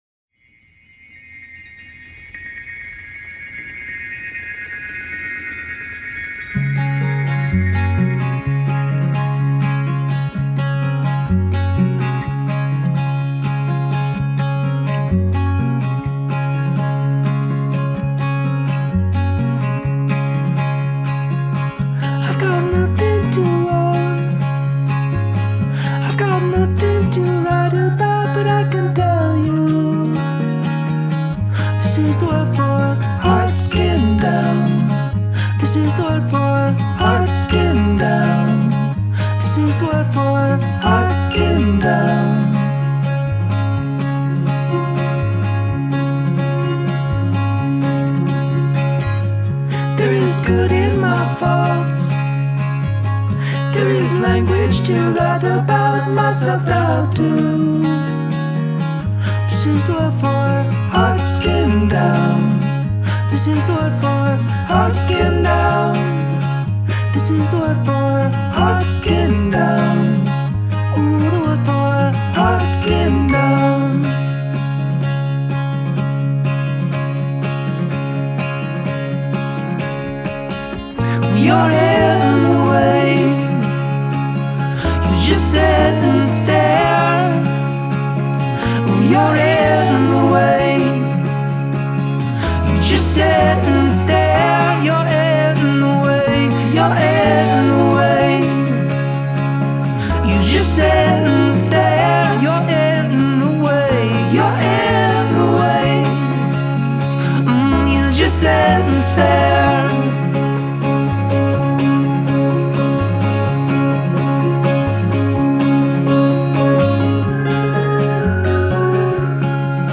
It’s a shining example of folk music done right.